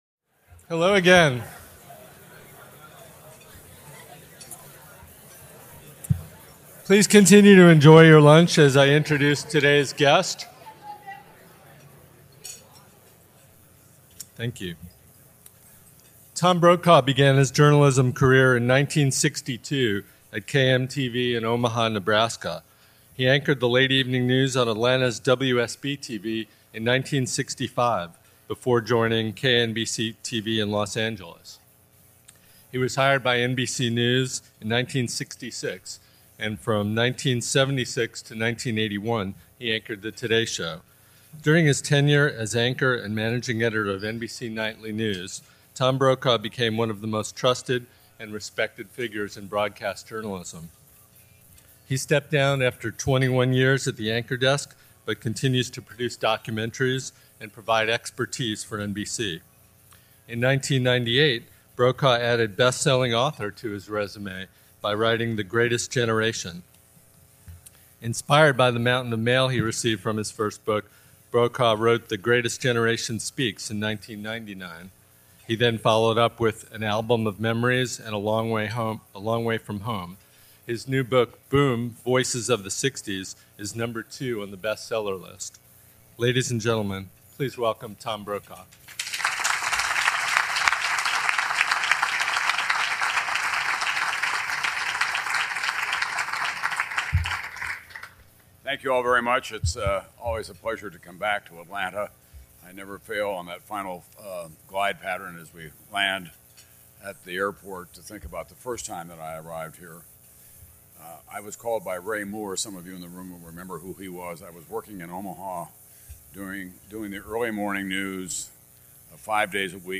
Newsmaker Luncheon - Tom Brokaw, former NBC Nightly News anchor | Georgia Podcast Network